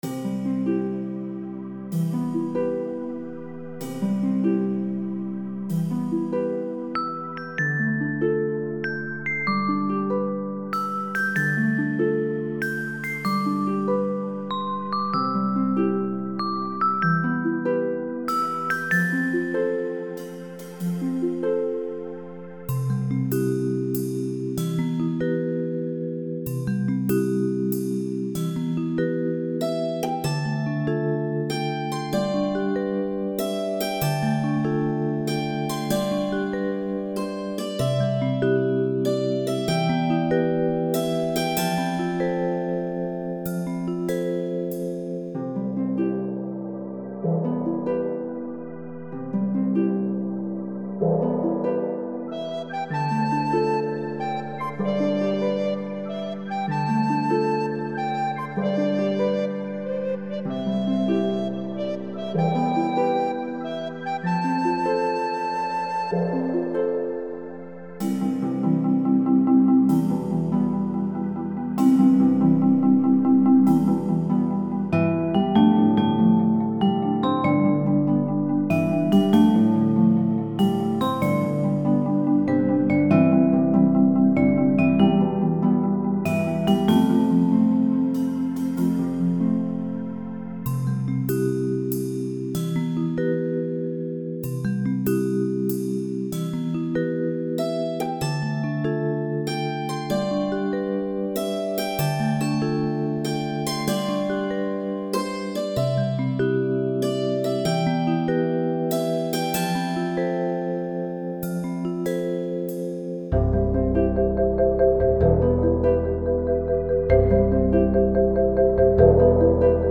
Melody instruments featured in the backing track include:
• glockenspiel in Verse 1
• dulcimer in Verse 2
• recorder in Verse 3
• guitar in Verse 4
• ensemble in the coda